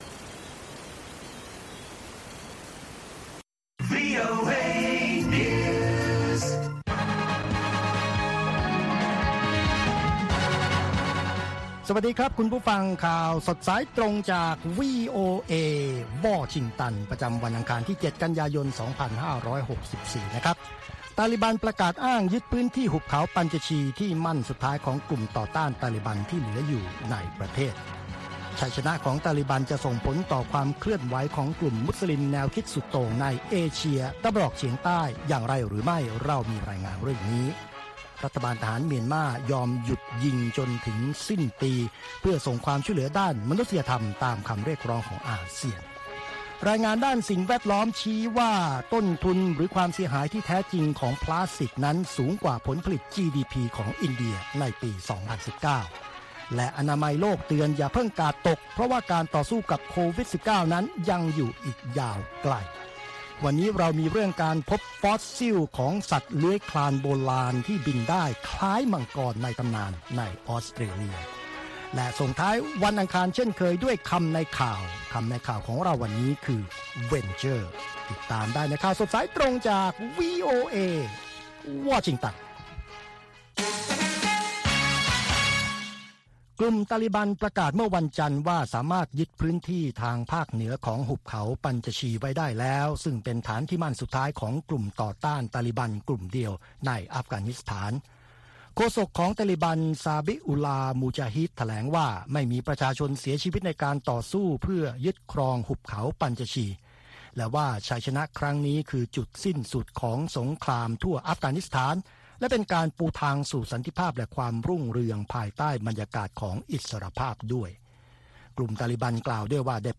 ข่าวสดสายตรงจากวีโอเอ ภาคภาษาไทย ประจำวันอังคารที่ 7 กันยายน 2564 ตามเวลาประเทศไทย